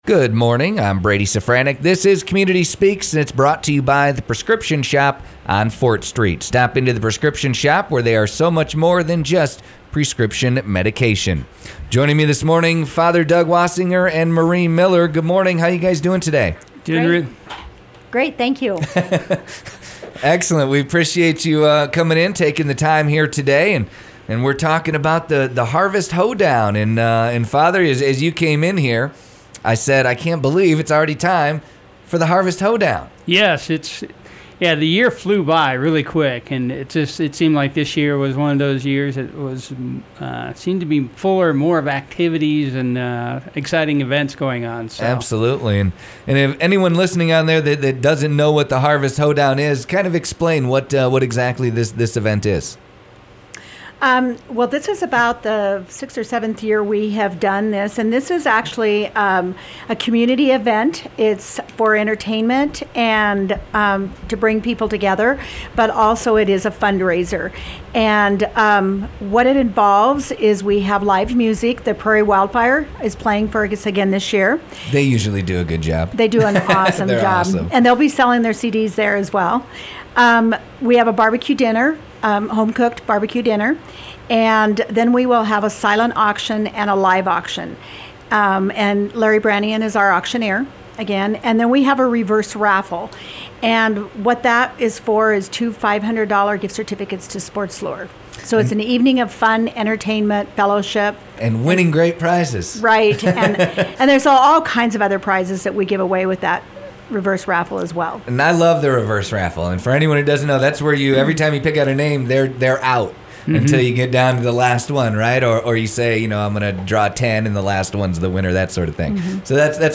We discussed this year’s Harvest Hoedown, which is at Crazy Woman Square in Buffalo on Sunday, September 9th @ 4:00 pm. Listen to the full interview…